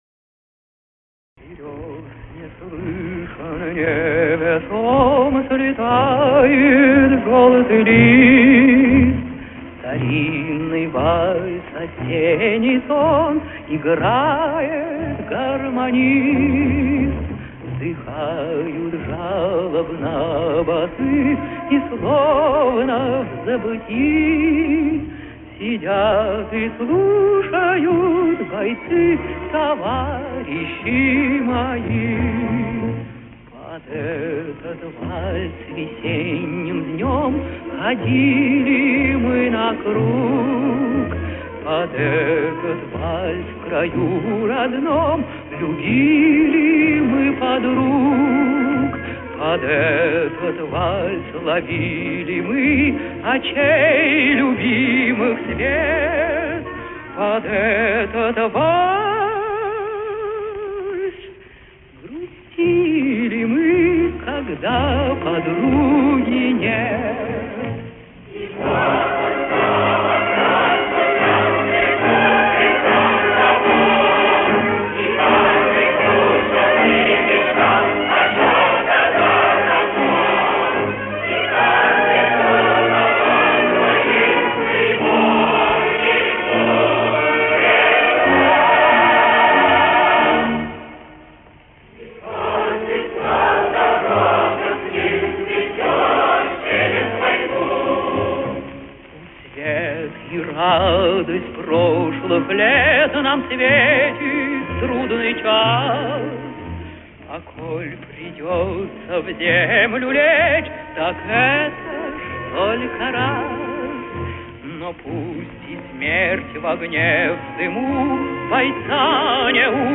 Некоторое улучшение качества